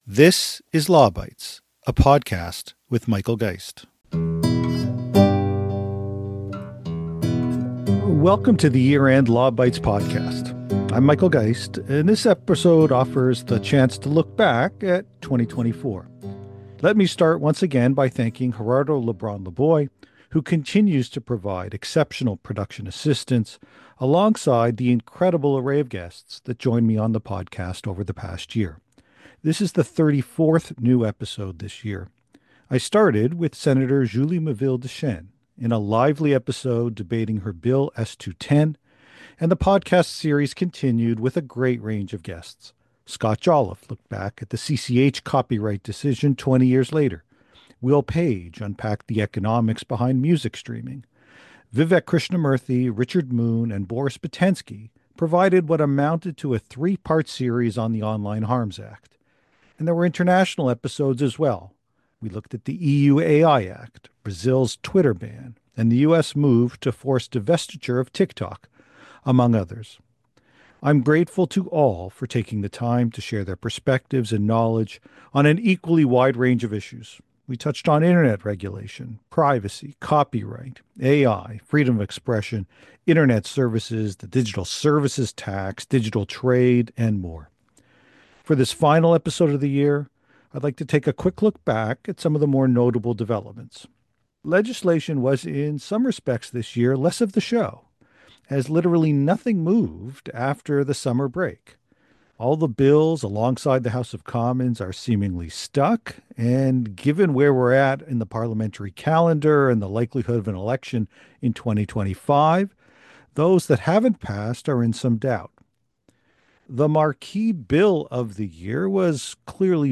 For this final Law Bytes podcast of 2024, I go solo without a guest to talk about the most significant developments in Canadian digital policy from the past year.